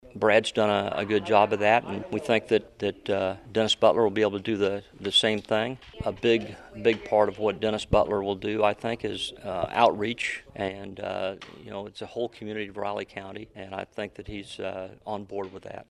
Law Board Chair Craig Beardsley says Butler made a good impression with his experience.